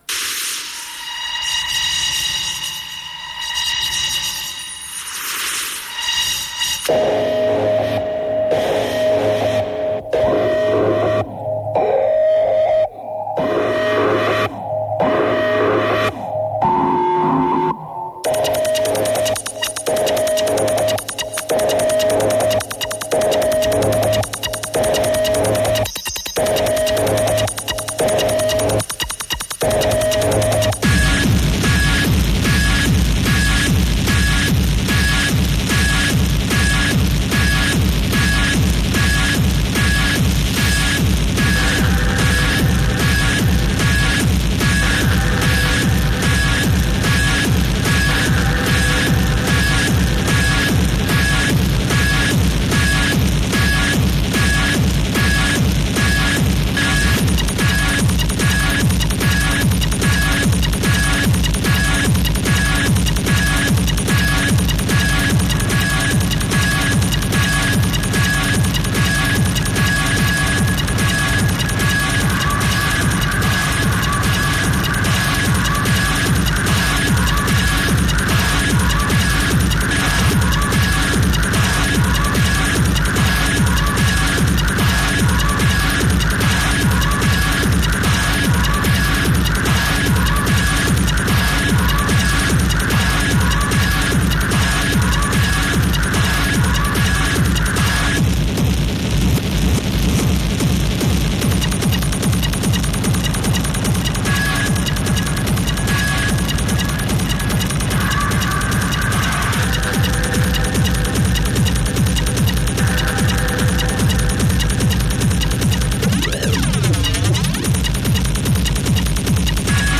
venue Flemington Racecourse